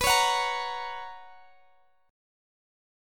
BM7sus2 Chord (page 2)
Listen to BM7sus2 strummed